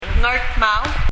ネイティブ・パラワンの発音を聴いてみましょう
発音例を聴いても、[d]は聴こえない、ほとんど無声音になってます。
しいてカナ表記すれば、「ガルトゥマウ」でしょうか？？？？